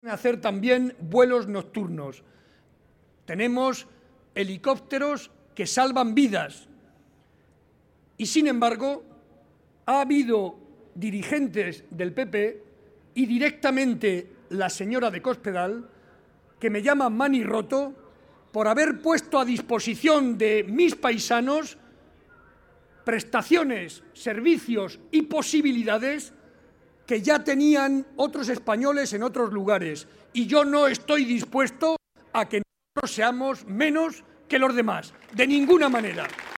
Audio Barreda mitin Guadalajara 4